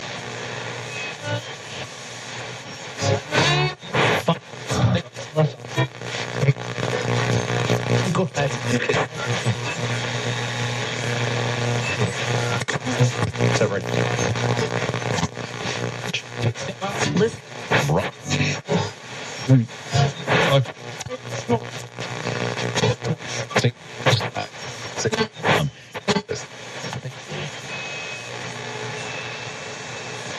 Звуки радио, радиопомех
Погрузитесь в атмосферу ретро-радио с коллекцией звуков помех, шипения и настройки частот.
Звук ручного поиска радиочастоты